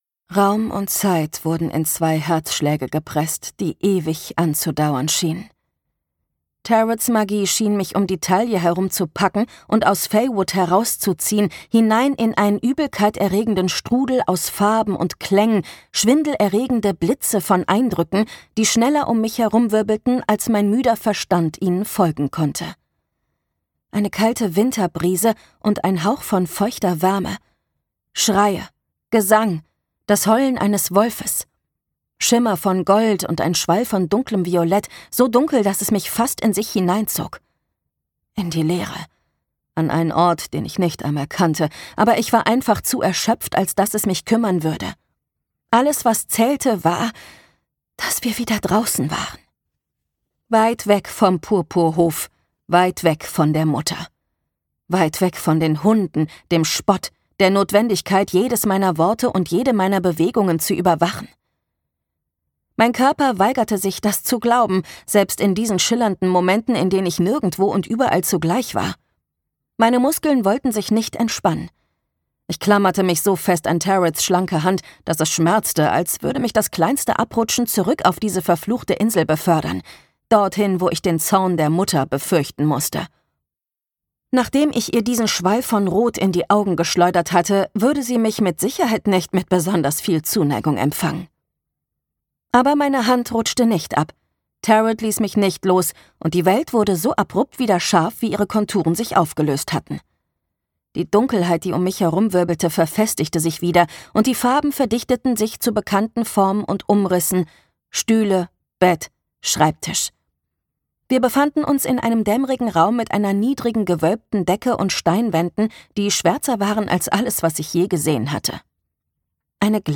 Gekürzt Autorisierte, d.h. von Autor:innen und / oder Verlagen freigegebene, bearbeitete Fassung.
Zur Sprecherin